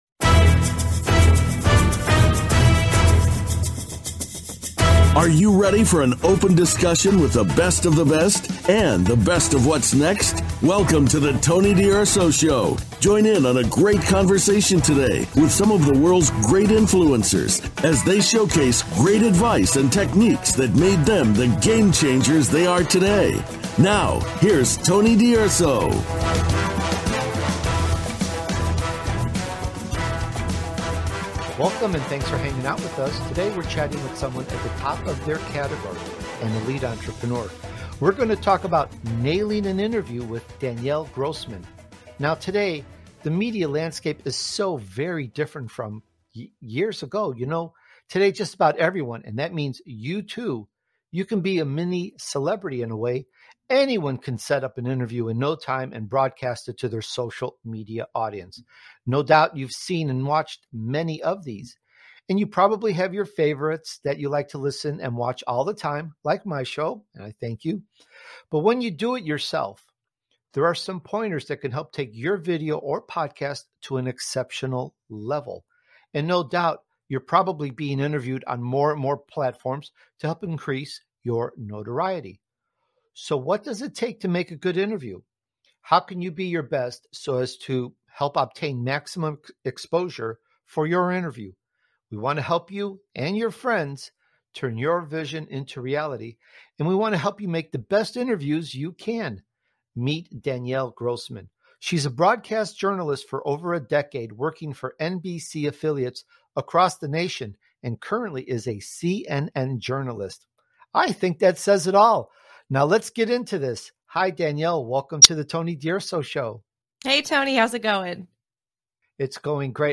Talk Show Episode
I interview some of the most successful people in the world, whom I call Elite Entrepreneurs. They share their journey and provide guidance & advice you can use to take things up another level.